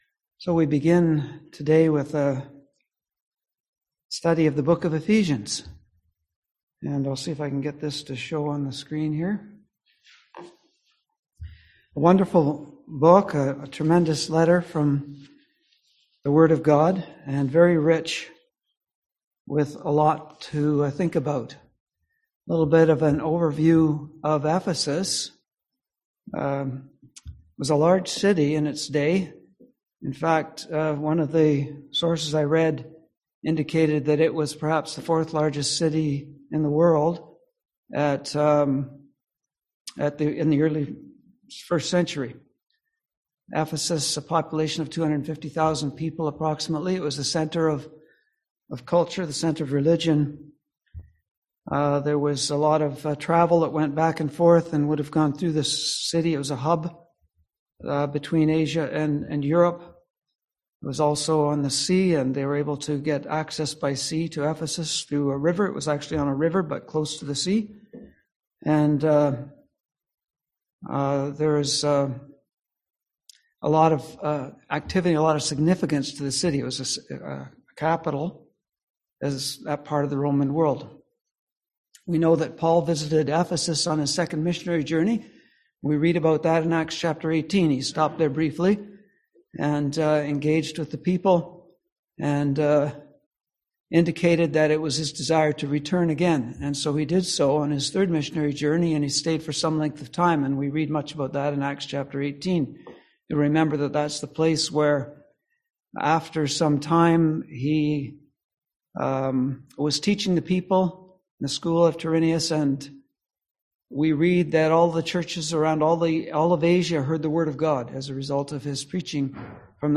Passage: Ephesians 1:1-14 Service Type: Seminar